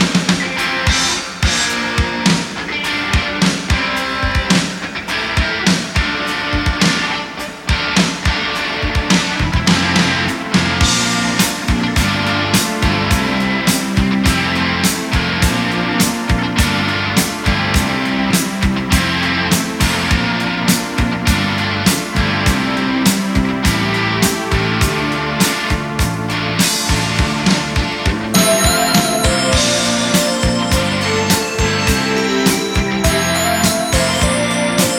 Rock Pop
Жанр: Поп музыка / Рок